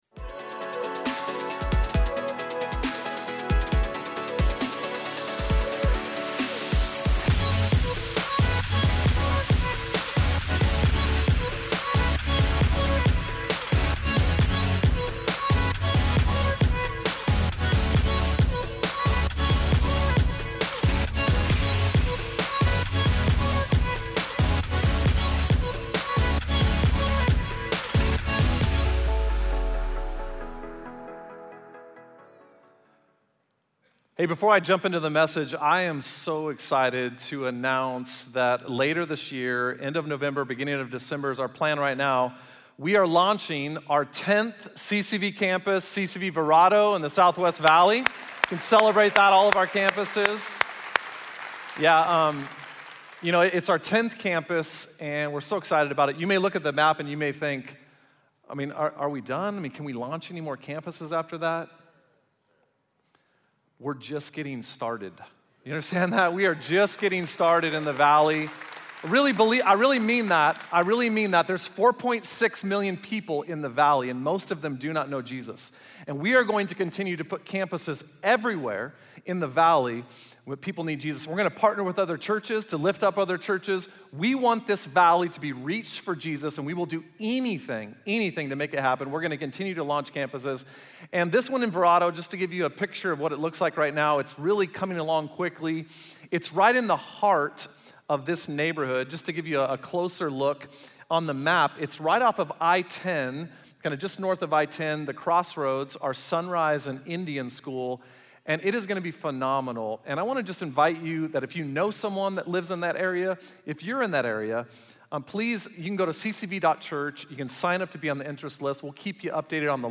Missed church service this weekend or want to hear the message again?